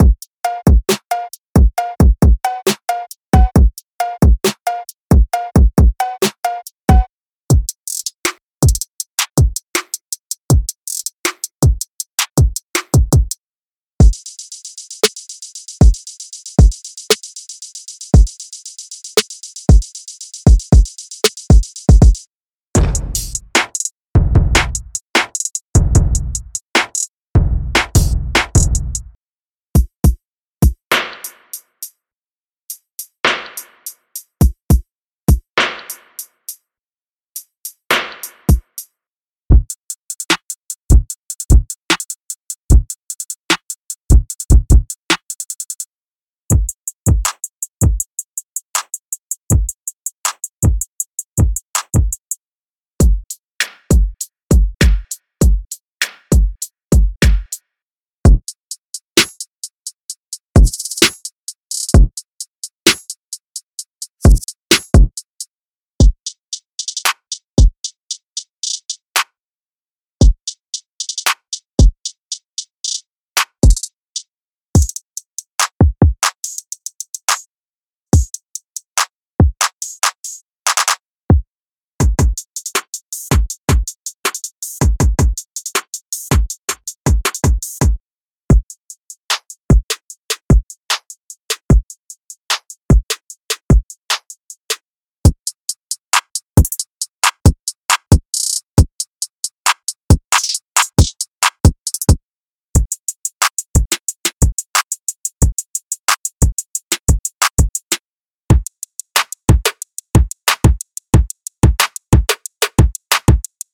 • 99 Kicks
• 138 Closed & Open Hats
Drum Samples Demo